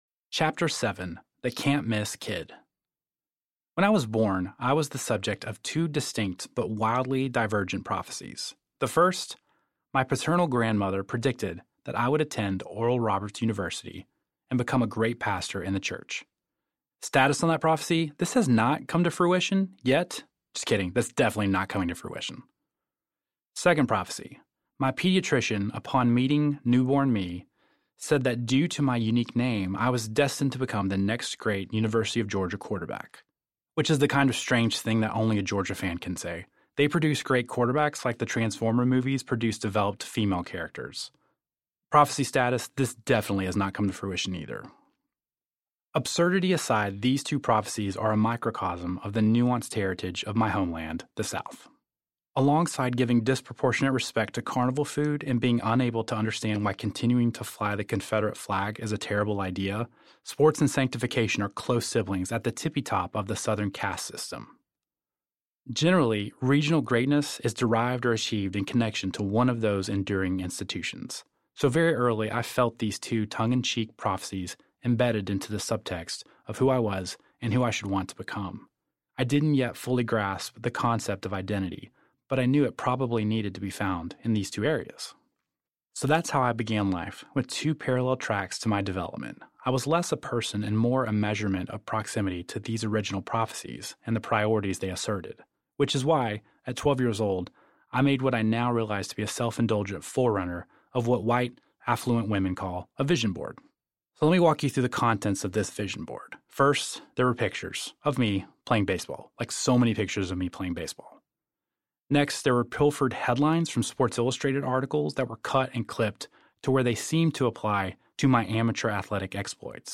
The Wondering Years Audiobook
Narrator
5 hrs. – Unabridged